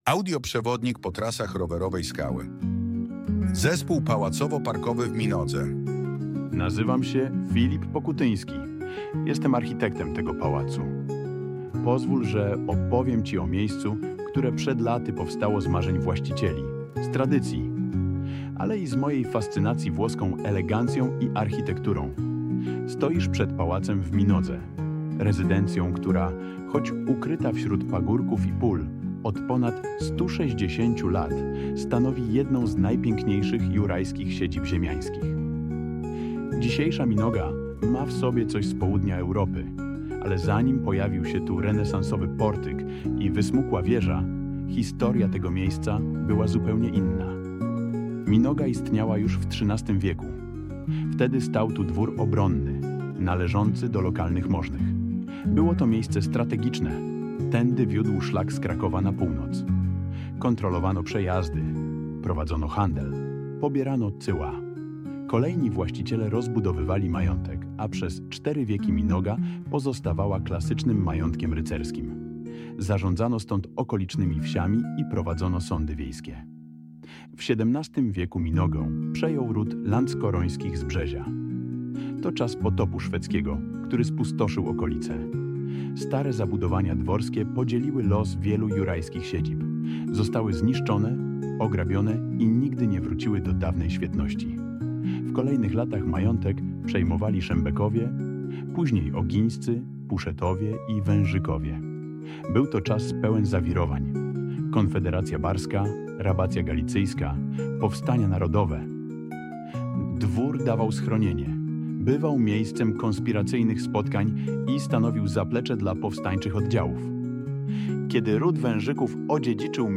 Palac-w-Minodze---audioprzewodnik-mi3l9p6q.mp3